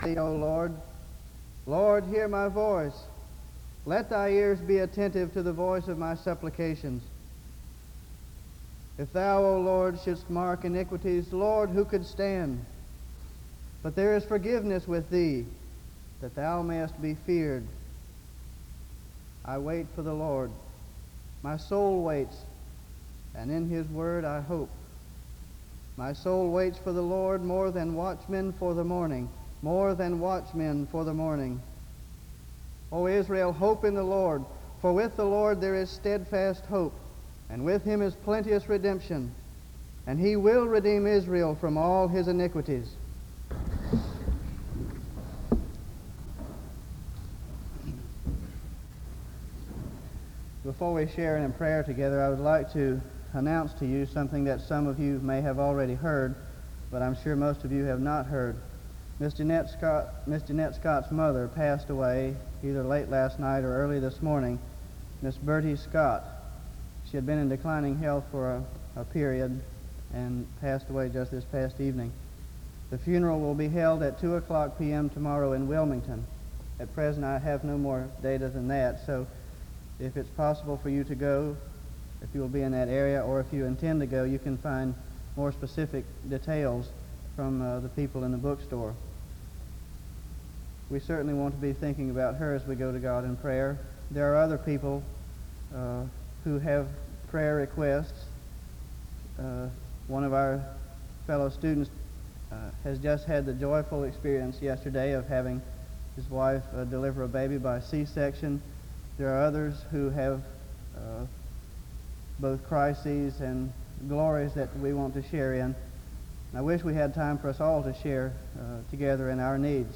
The service starts with a scripture reading from 0:00-0:43. An announcement is given from 0:51-2:00. A prayer is offered from 2:02-3:24. An introduction to the speaker is given from 3:32-6:11.